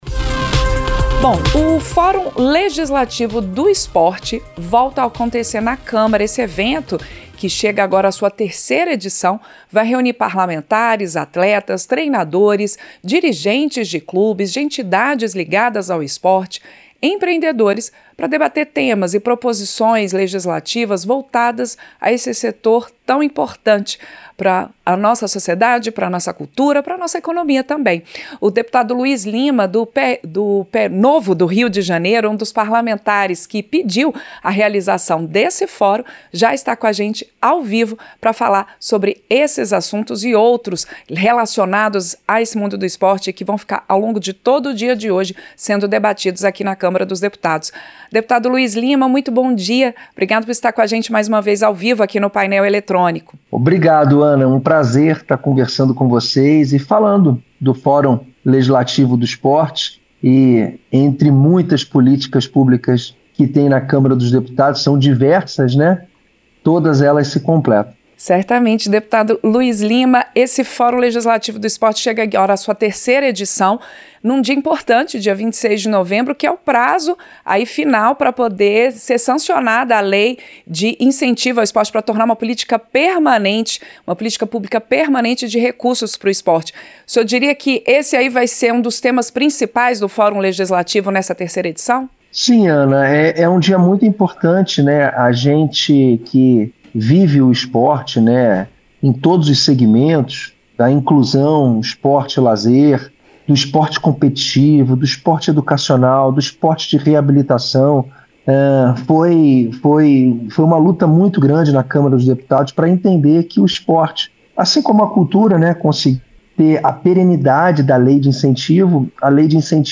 Entrevista - Dep. Luiz Lima (PL-RJ)